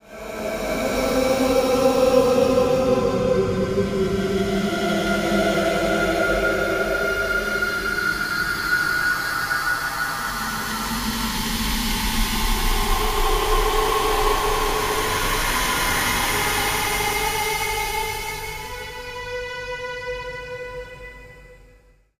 Ambient horror
ambient_horror_0.ogg